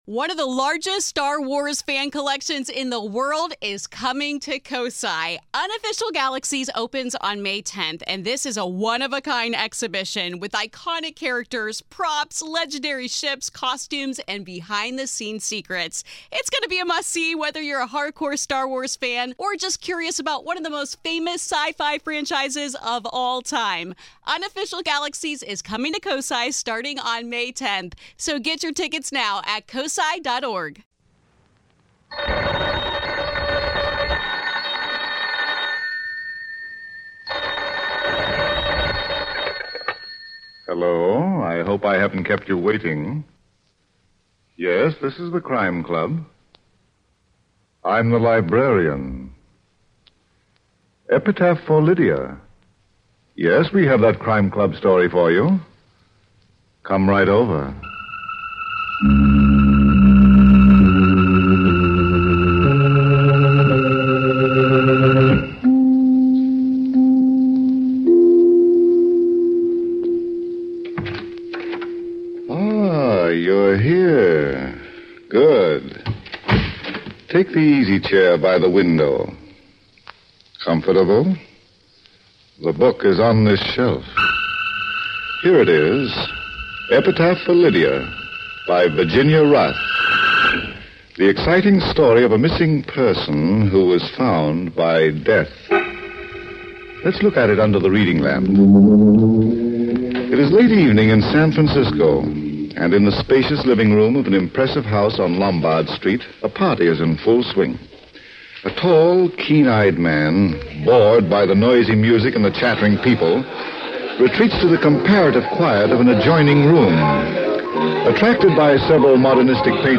On this episode of the Old Time Radiocast we present you with two stories from the classic radio program The Crime Club!